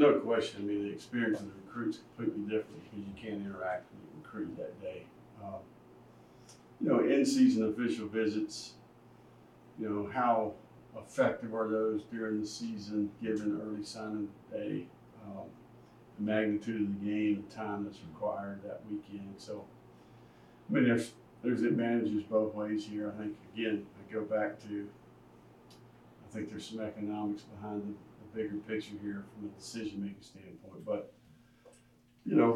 Before the event, he addressed the media.